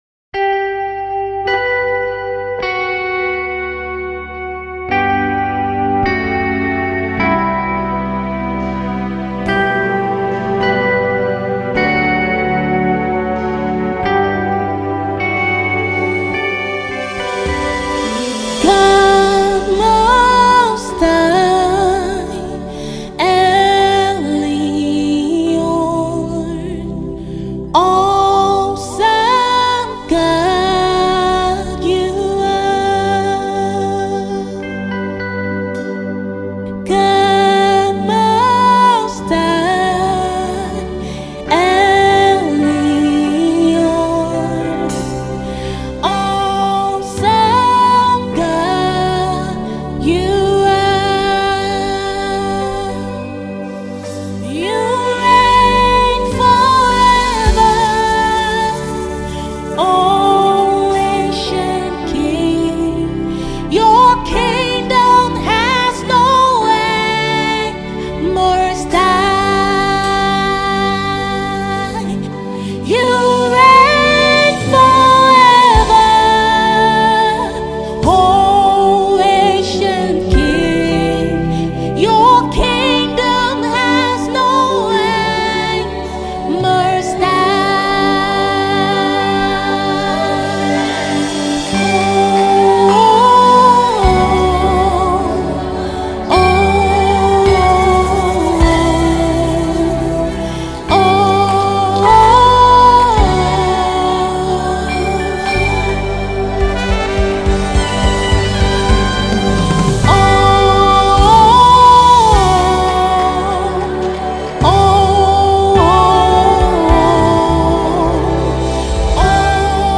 Gospel music
worship song